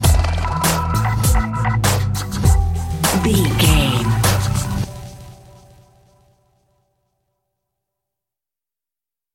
Aeolian/Minor
synthesiser
drum machine
hip hop
Funk
neo soul
acid jazz
energetic
bouncy
Triumphant
funky